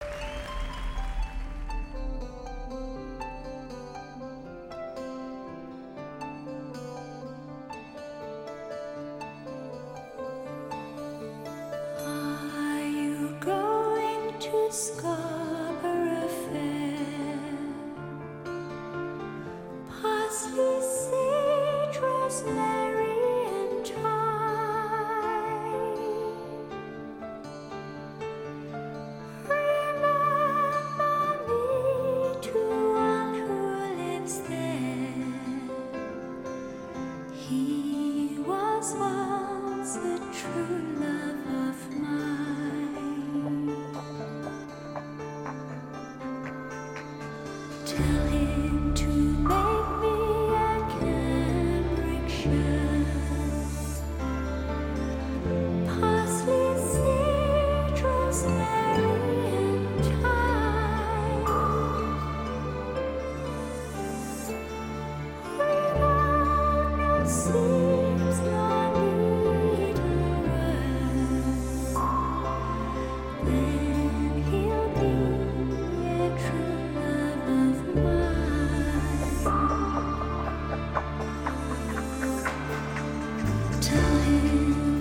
全场演唱会充满蓝色月夜的感伤基调，而舞台背景上象
吐字清晰，